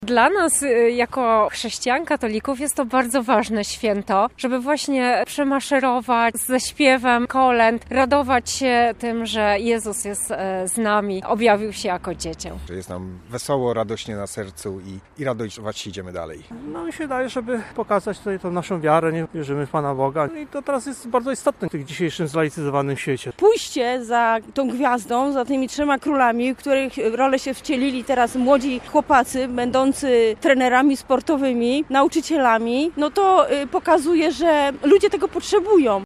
3bus2i12ciy0bxm_orszak_kaliski.mp3